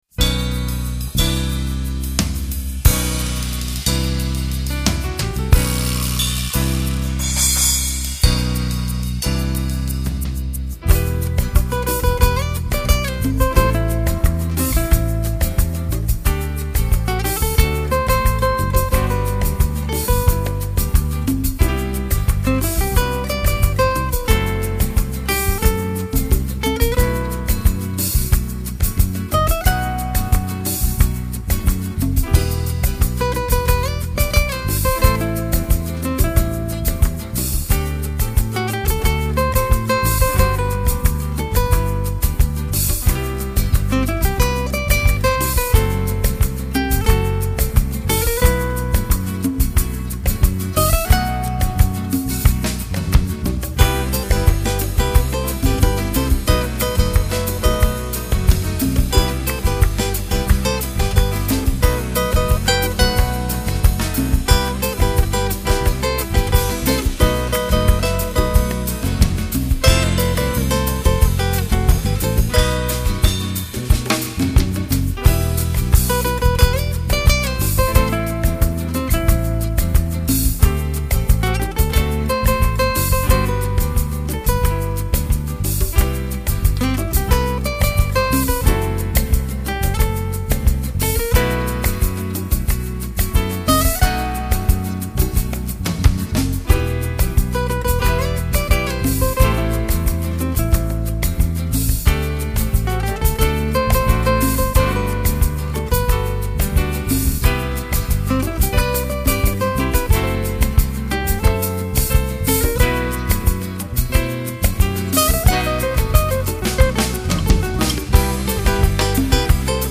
【弗拉门戈吉他】
音乐风格：Folk Rock / Acoustic / Folk